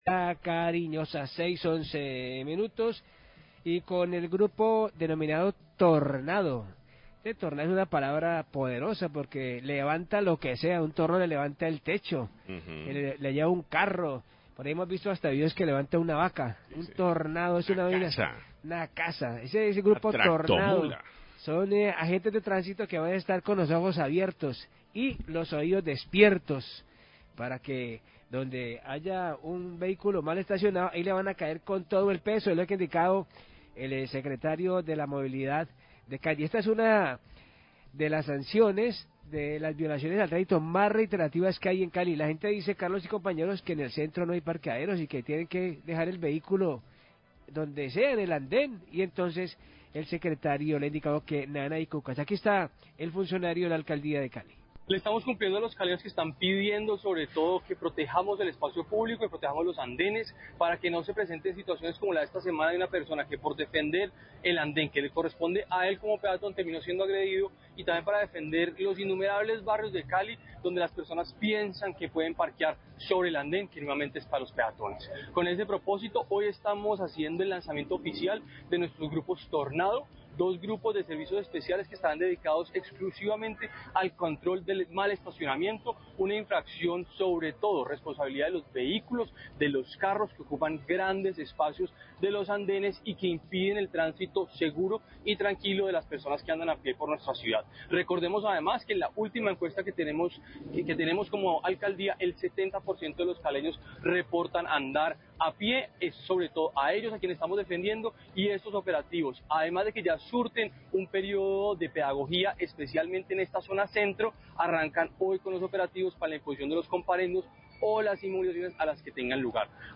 Radio
Secretaría de Movilidad de Cali informó las nuevas medidas que implementará para disminuir los casos que se presentan de invasión del espacio público en la ciudad. Por medio de una rueda de prensa, el secretario de Movilidad, Gustavo Orozco, expuso al Grupo Tornado, un conjunto de agentes de tránsito que, apoyados por la policía metropolitana, realizarán operativos relámpagos de control en las vías de la ciudad.